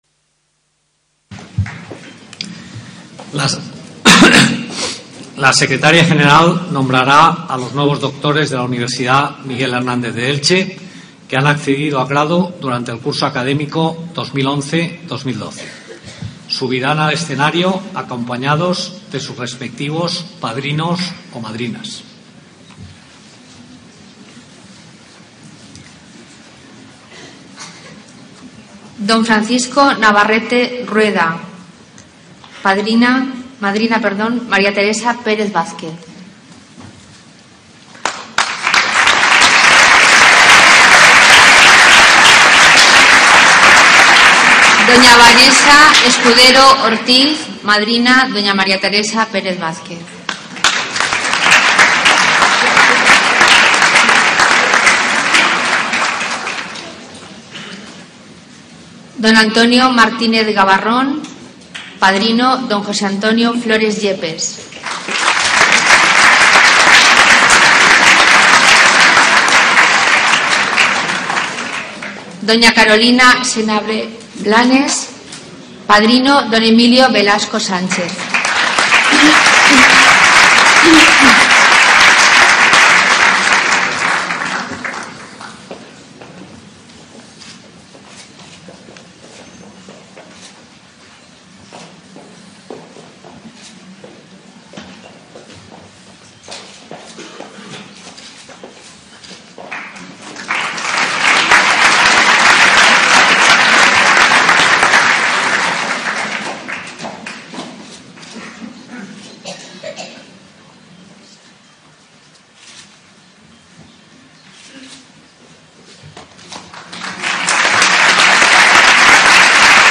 28 enero 2013 Acto Santo Tomás de Aquino e Investidura como Doctor Honoris Causa del Sr. D. Paul V. Mockapetris